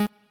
left-synth_chord21.ogg